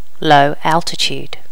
Additional sounds, some clean up but still need to do click removal on the majority.
low altitude.wav